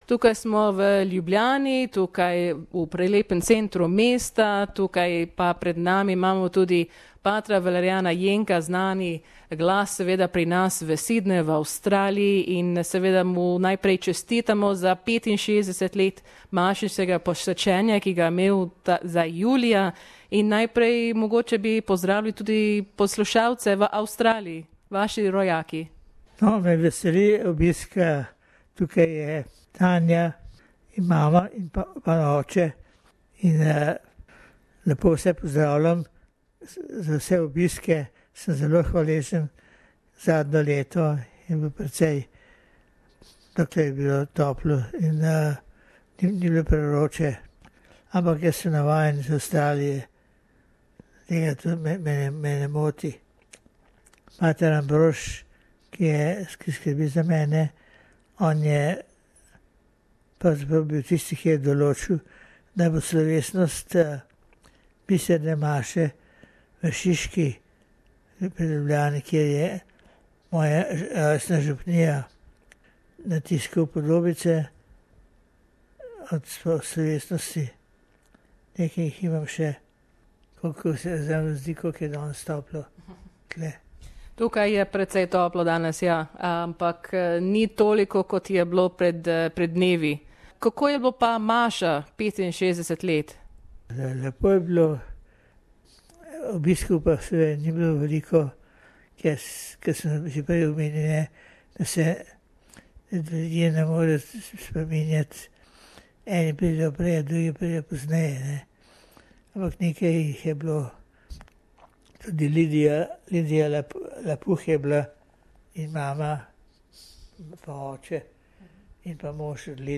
V tem pogovoru vse avstralske Slovence lepo pozdravlja in govori o praznovanju.